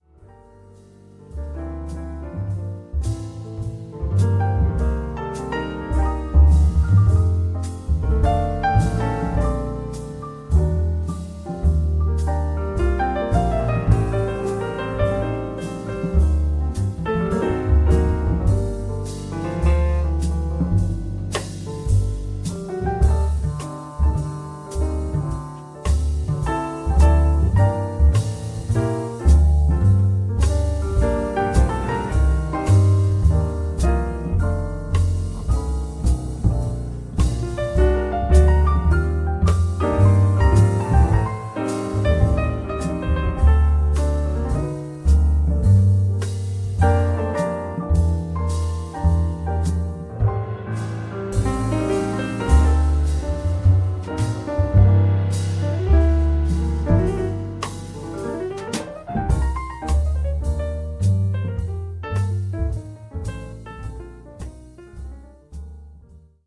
• Pianist / Orgelspieler